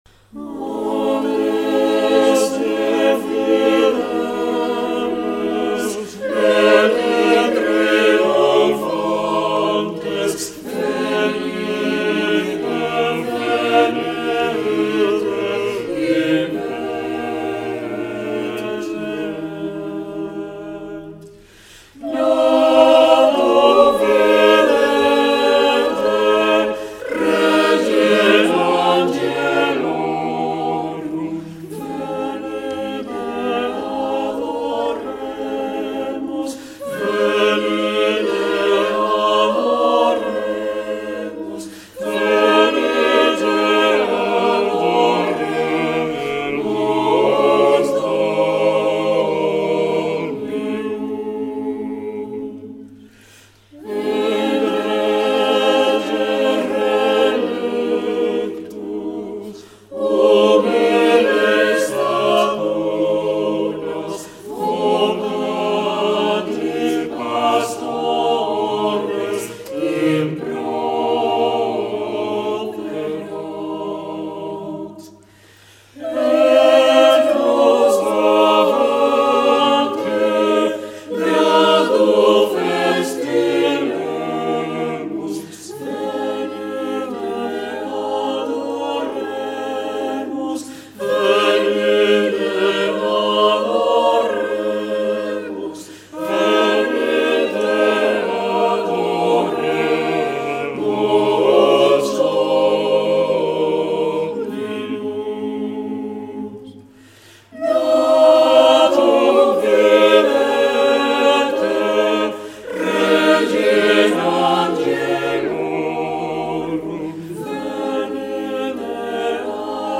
Coral (Santa María la Mayor)
9-Coral_Santa_Maria_la_Mayor.mp3